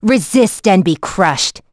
Hilda-Vox_Skill4.wav